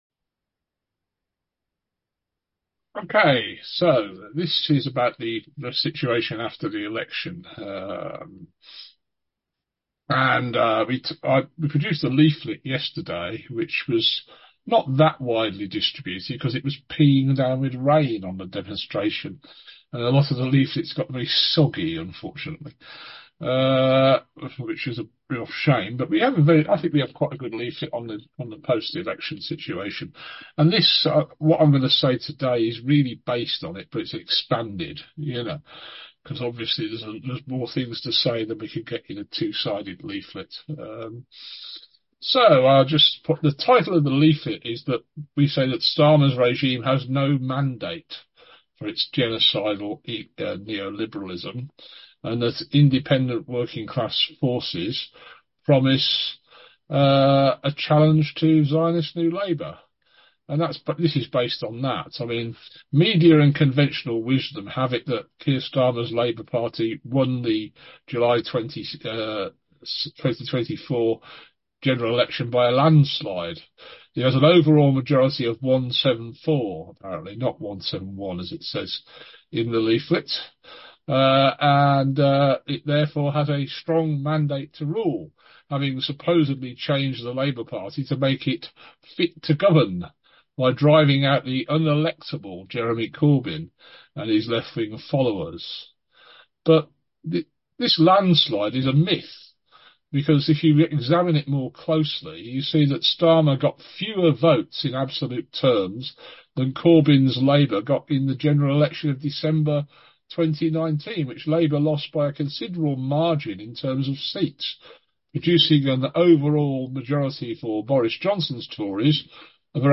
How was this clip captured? The presentation and discussion at our forum this afternoon can also be heard here as a podcast